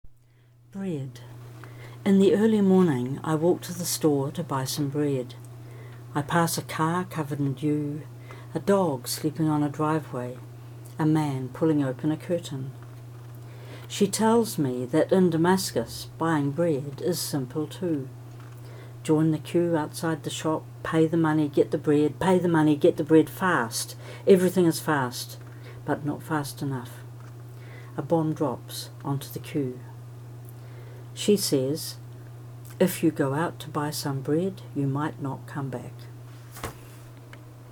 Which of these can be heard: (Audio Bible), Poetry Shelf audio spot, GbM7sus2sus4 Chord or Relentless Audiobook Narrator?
Poetry Shelf audio spot